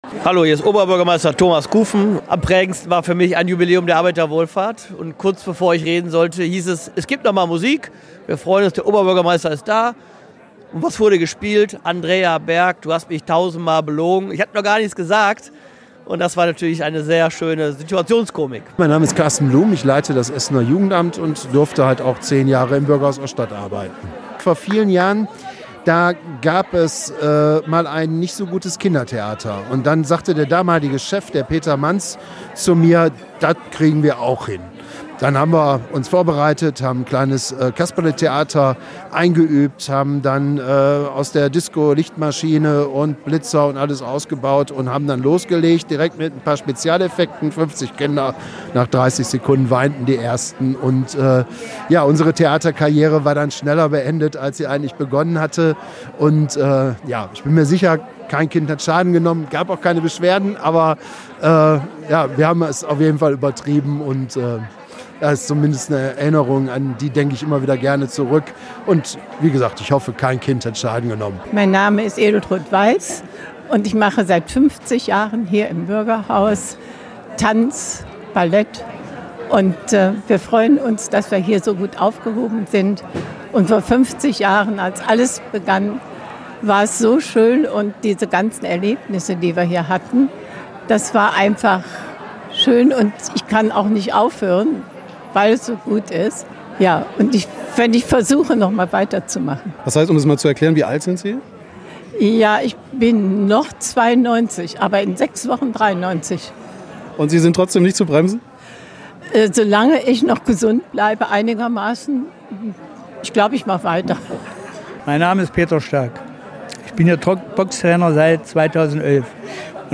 Uns und weiteren Gästen haben sie ihre Geschichten, die sie mit dem Bürgerhaus Oststadt verbinden, auch nochmal erzählt.